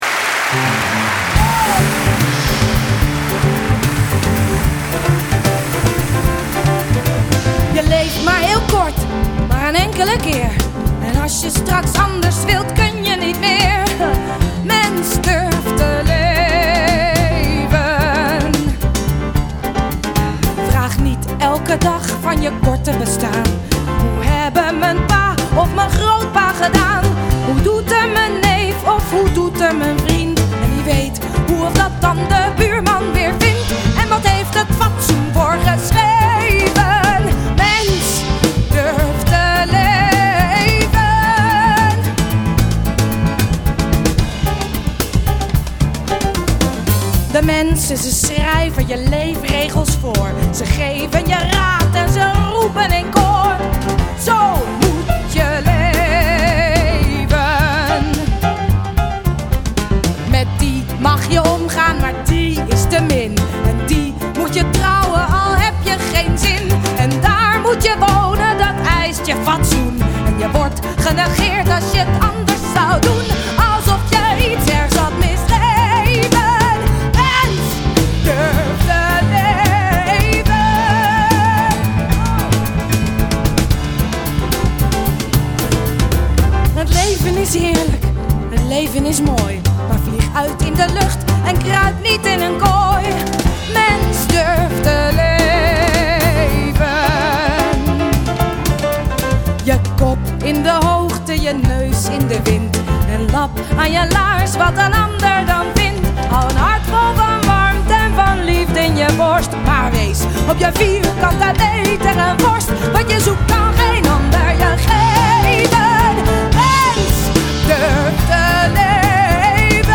OK, nog eentje dan, een flitsende uivoering van